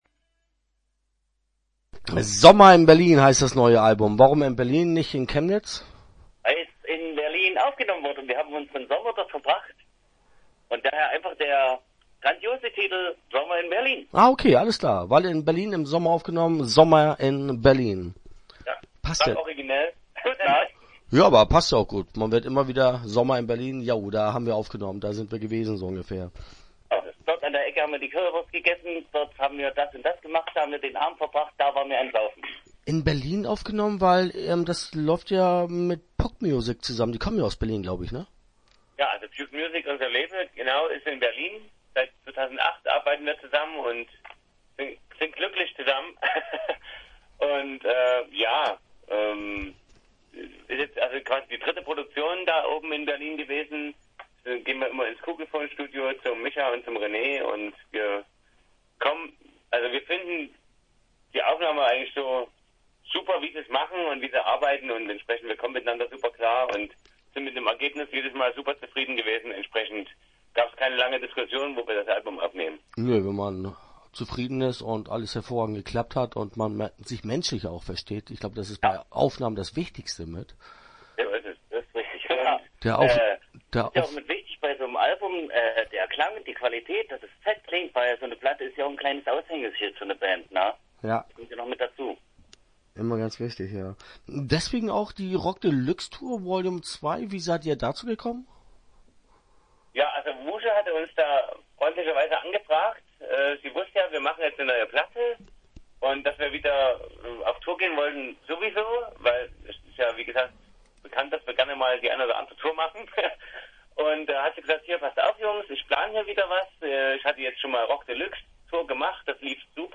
Interview Teil 1 (11:14)